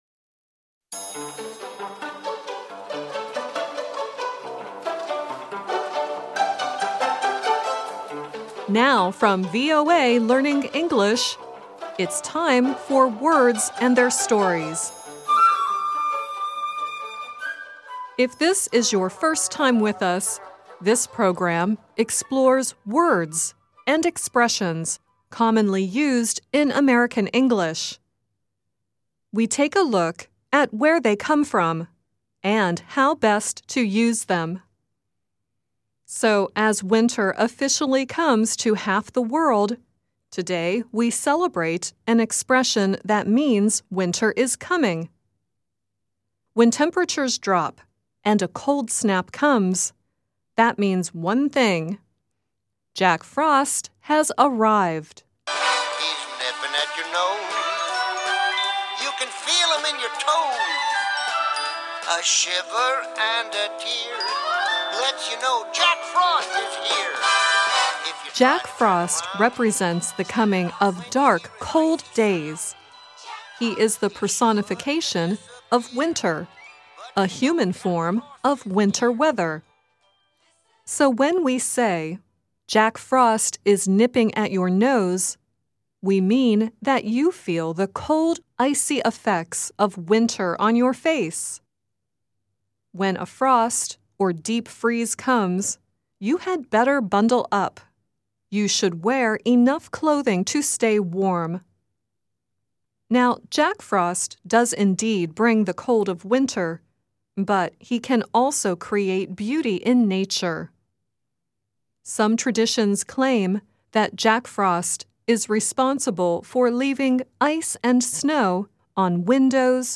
The song earlier in the program is from the 1979 animated television “Jack Frost.” The song at the end is Nat King Cole singing “The Christmas Song.”